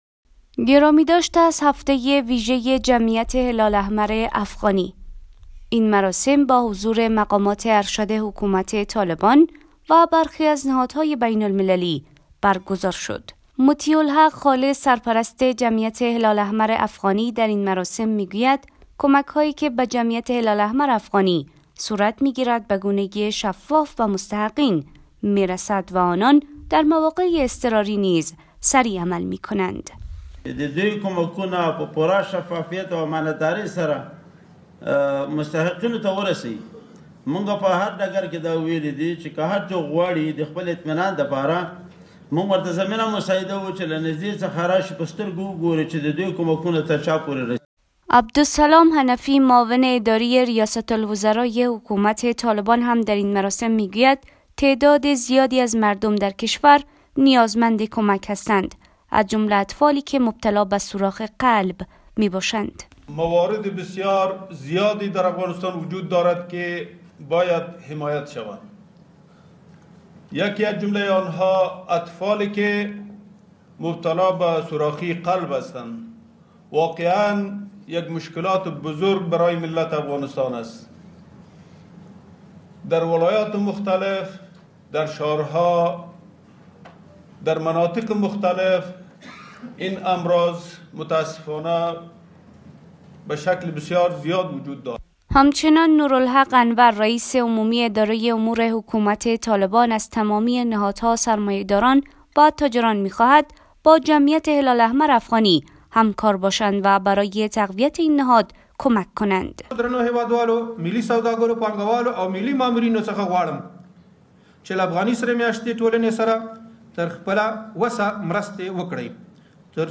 در مراسم گرامیداشت هفته جمعیت هلال احمر در کابل که با حضور مقامات طالبان، نمایندگان نهادهای امداد رسان و برخی دیگر از نهادهای بین المللی برگزار شد، مسوولان هلال احمر افغانستان بر توزیع شفاف کمک های مردمی و جهانی به نیازمندان تاکید کردند.